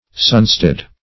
Search Result for " sunsted" : The Collaborative International Dictionary of English v.0.48: Sunsted \Sun"sted\, n. [Sun + stead a place.]